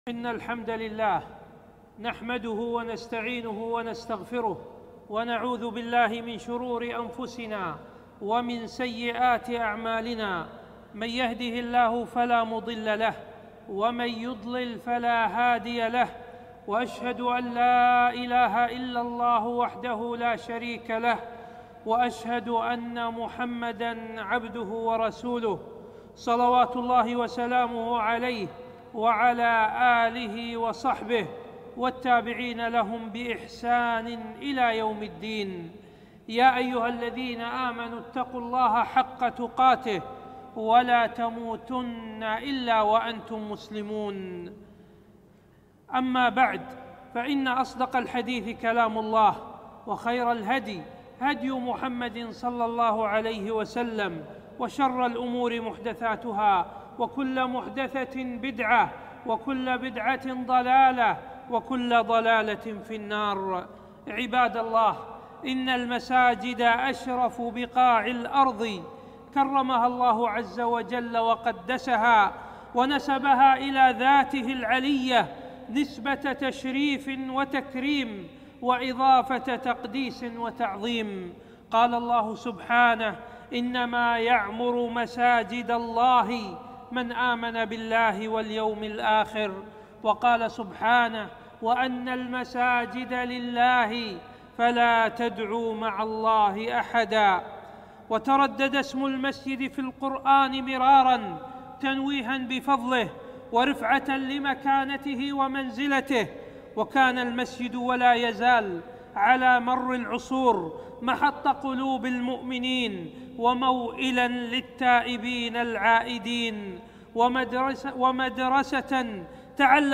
خطبة - مكانة المسجد وآدابه